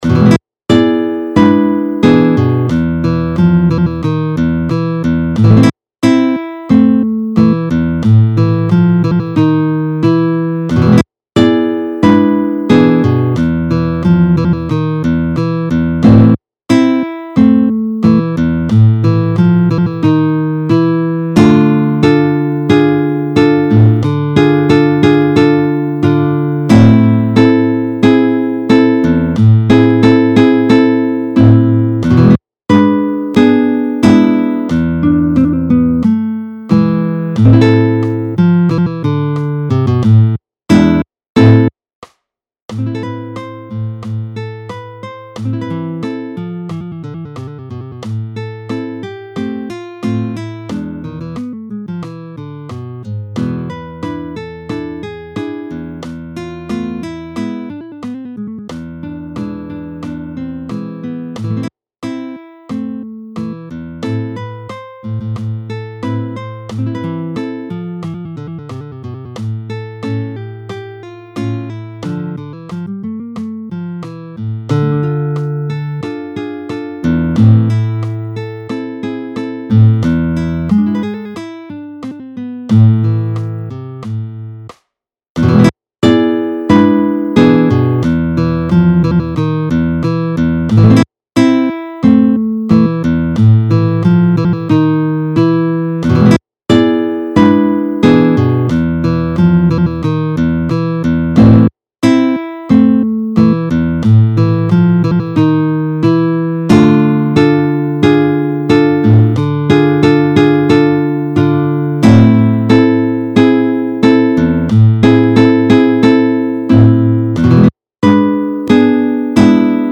Sfizioso e famosissimo tango Argentino!